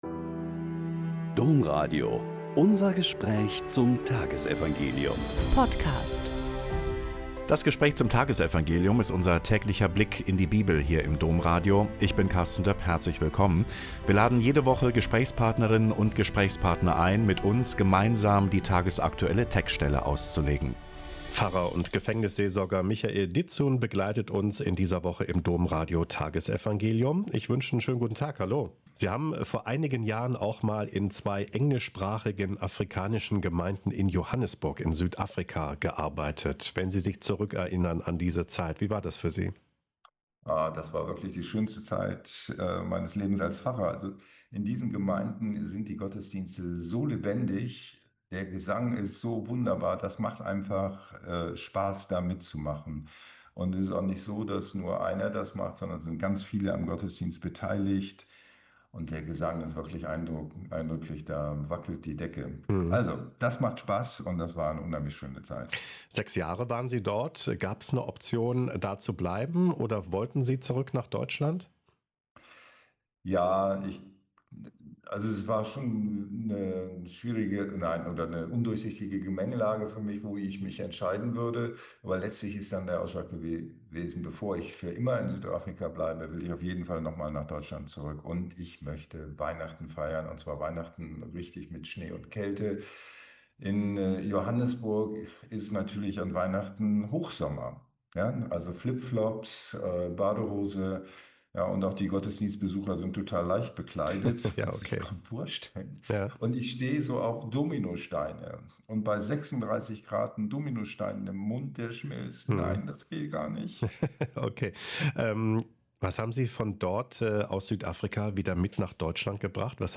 Lk 14,25-33 - Gespräch